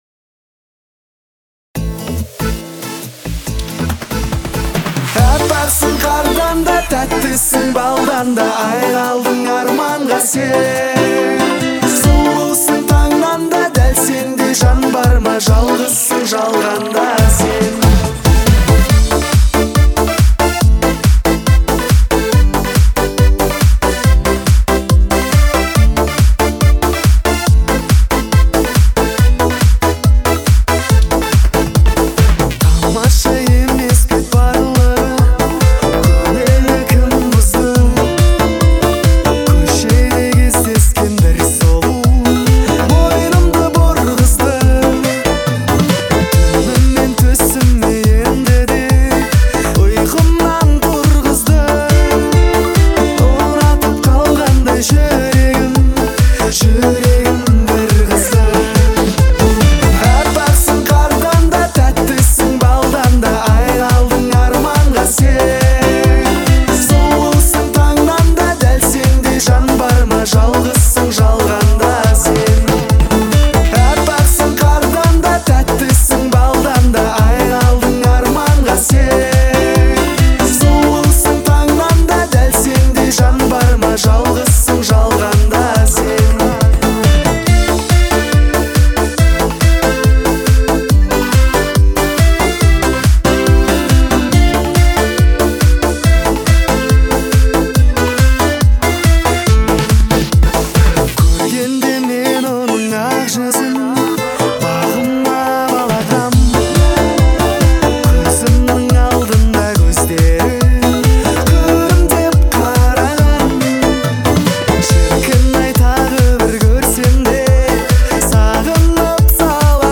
Взрывообразные мелодические линии и душевное исполнение